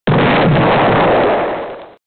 Deltarune Explosion Sound - Botón de Efecto Sonoro